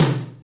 TOM TOM133.wav